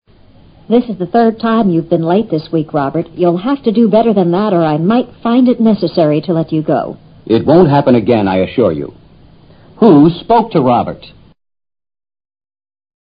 托福听力小对话【24】
从说话的口气看，是上级训斥下级。